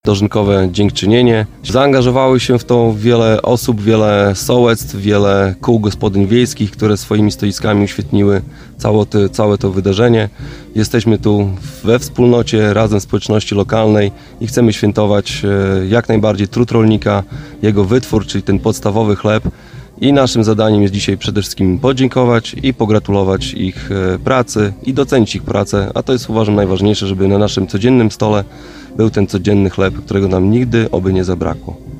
– Tradycja jest bardzo ważna, dlatego każdego roku chcemy mocno zaakcentować ten dzień, w którym wspólnie świętujemy zakończenie żniw i całorocznej, ciężkiej pracy – mówi Karol Sobczak, burmistrz Olecka.